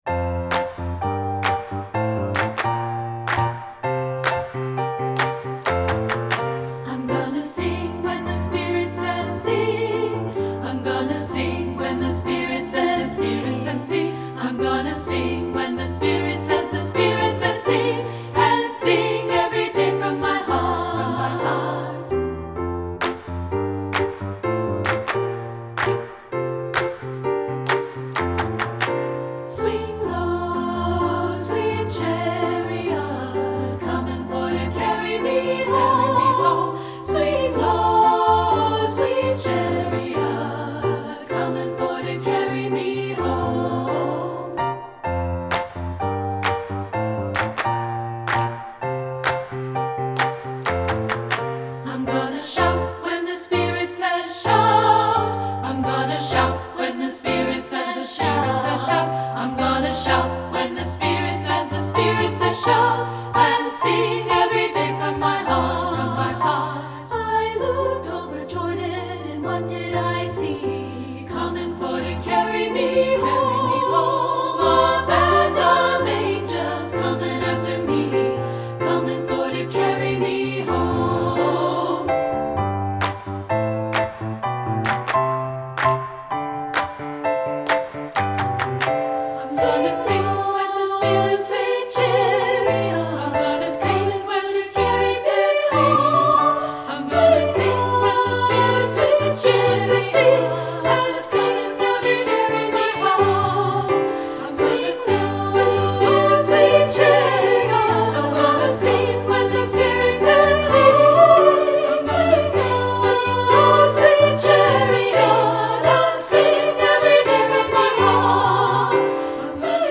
Voicing: 2-pt,Pno